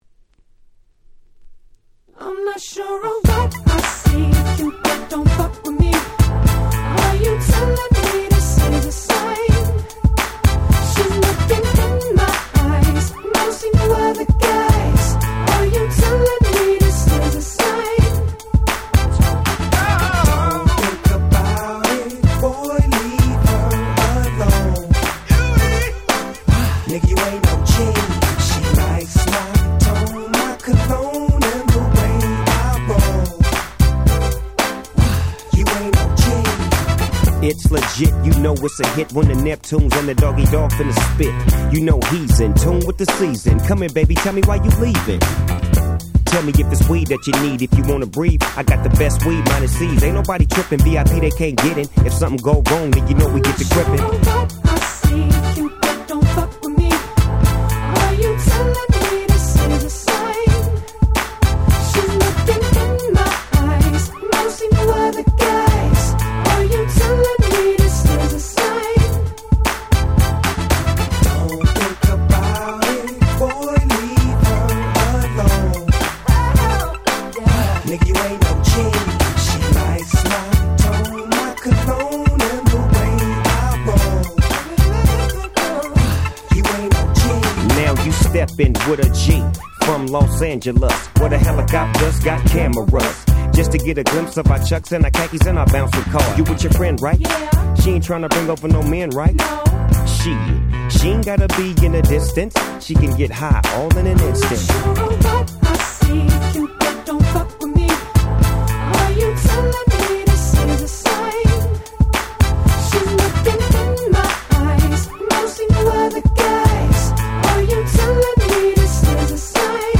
05' Big Hit Hip Hop !!